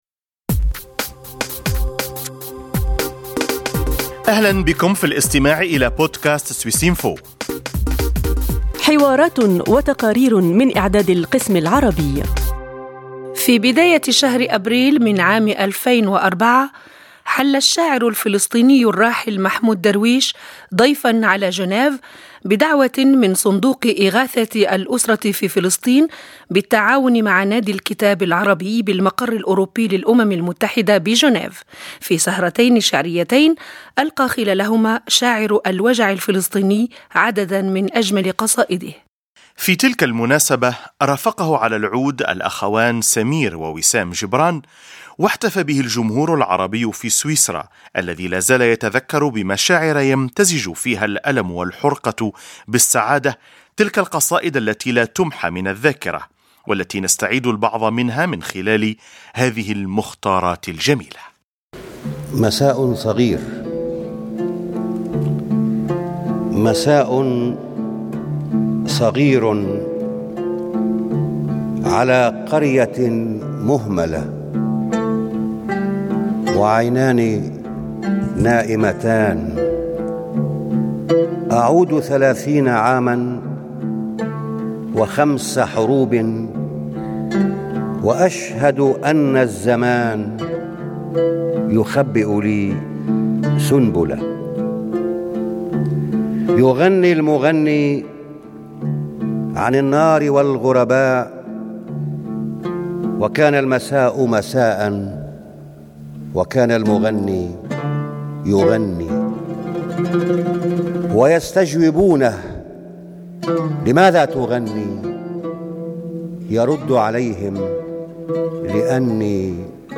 في ربيع 2004، استمع مئات الأشخاص في جنيف للشاعر الفلسطيني الراحل محمود درويش، وهو يُـلقي عددا من أجمل قصائده.